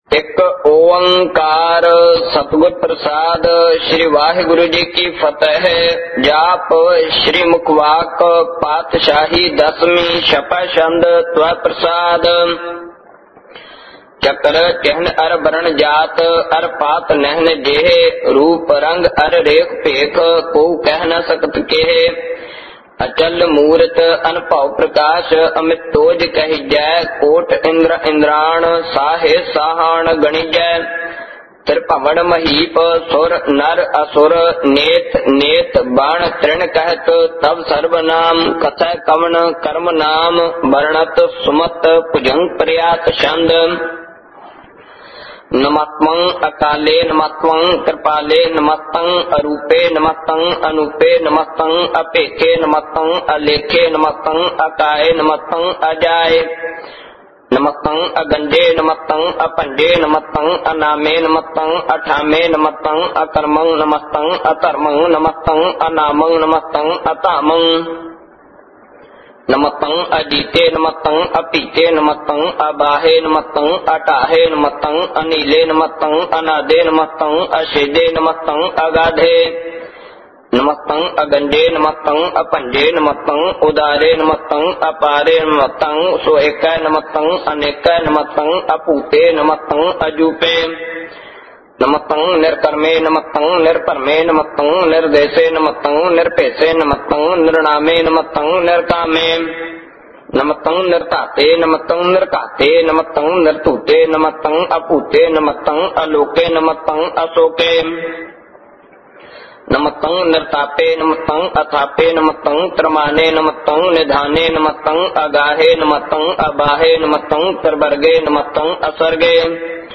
Album:Jaap.Sahib Genre: -Gurbani Ucharan Album Info